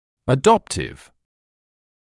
[ə’dɔptɪv][э’доптив]приёмный (о родителях); усыновивший, удочеривший (в т.ч. об учреждениях)